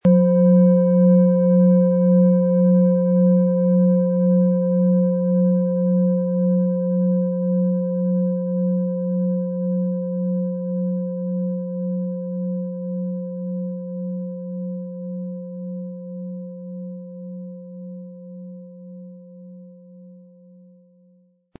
Planetenton 1
Lieferung inklusive passendem Klöppel, der gut zur Planetenschale passt und diese schön und wohlklingend ertönen lässt.
HerstellungIn Handarbeit getrieben
MaterialBronze